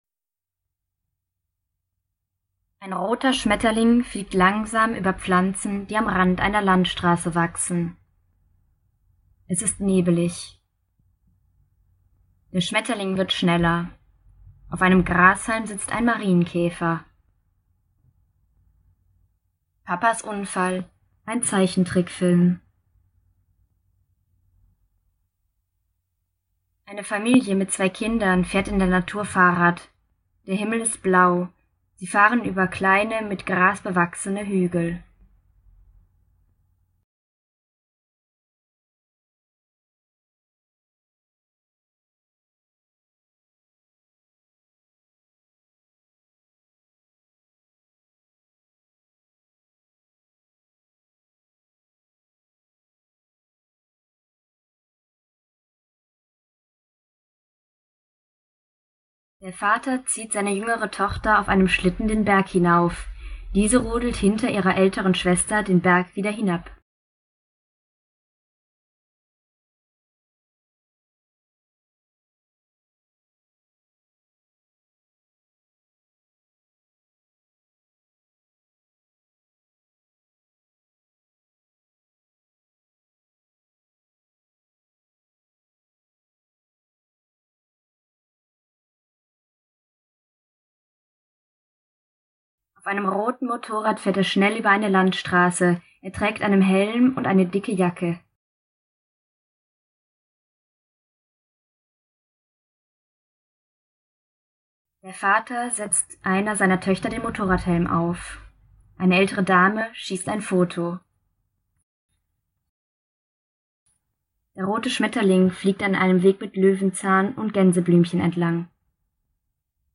Audiodeskription zum Film "Papas Unfall"
Die Audiodeskription ist so aufgenommen, dass sie zeitgleich mit dem Video anfängt.
Audiodeskription-Papas-Unfall.mp3